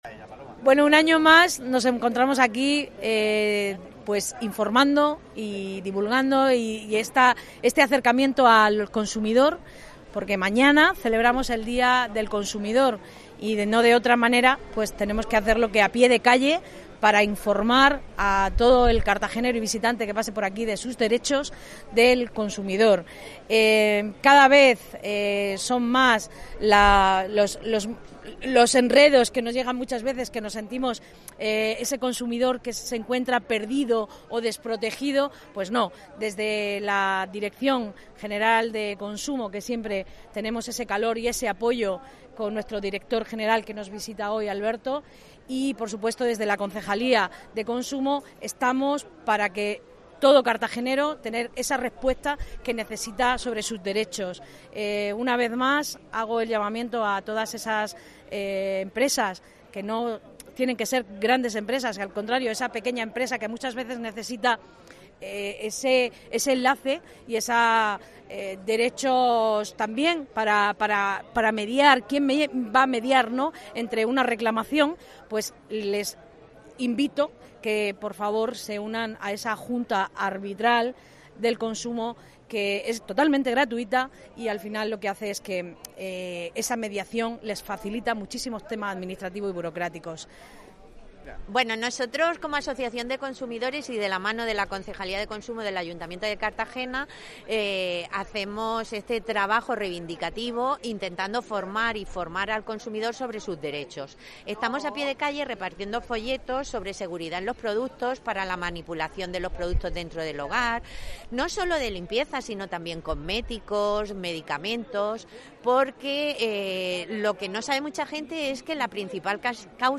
Enlace a Declaraciones de la edil Belén Romero